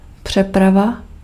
Ääntäminen
Tuntematon aksentti: IPA: /tʁɑ̃s.pɔʁ/